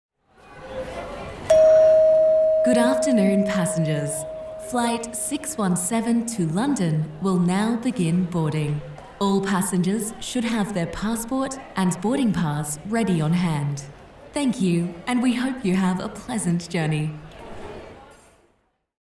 Female
English (Australian)
Live Announcer
Airport Announcement
Words that describe my voice are Authentic, Professional, Conversational.
All our voice actors have professional broadcast quality recording studios.
0505Flight_Announcement.mp3